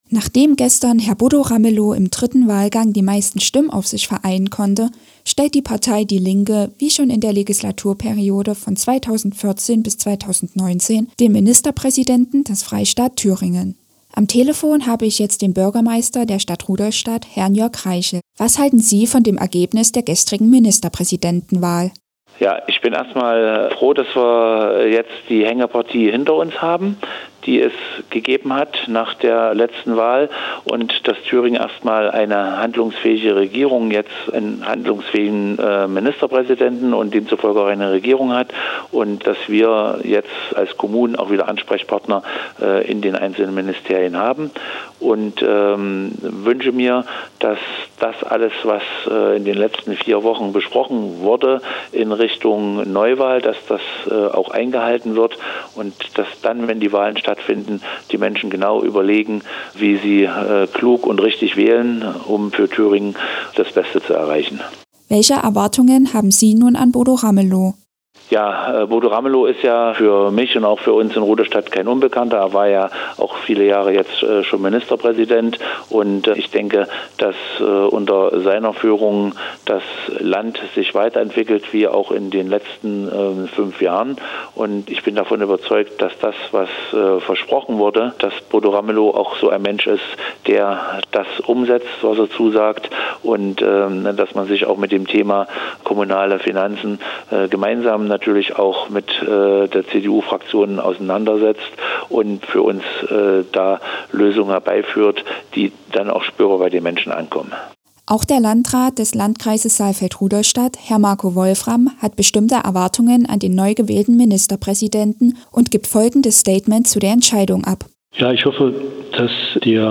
In der Sendereihe "Parlamentarischer Ouzo" berichten Thüringer Lokalsender regelmäßig aus einem vom Landtag zur Verfügung gestellten Senderaum in unmittelbarer Nähe zum Plenarsaal.
Reaktionen auf die Wahl Ramelows als Ministerpräsident von Marko Wolfram (SPD-Landrat Saalfeld) und Jörg Reichl (Bürgermeister Rudolstadt)